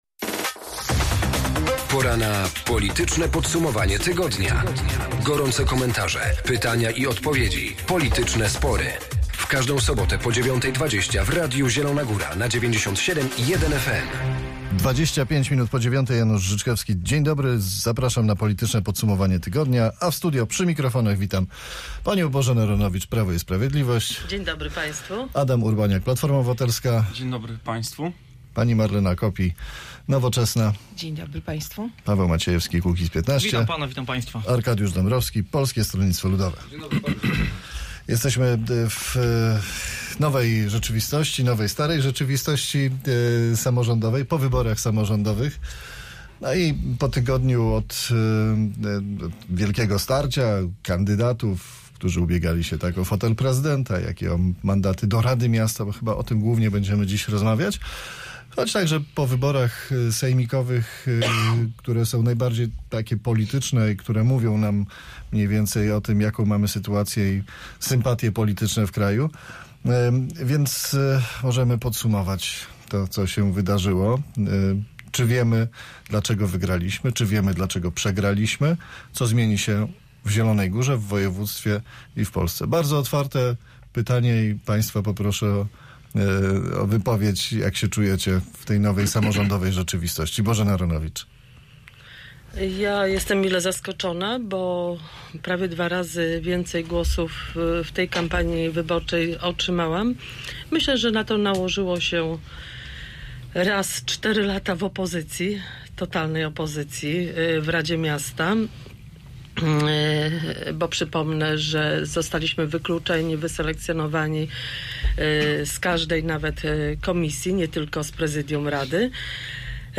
Gośćmi Politycznego Podsumowania Tygodnia byli: